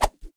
UI_Char_Remove.ogg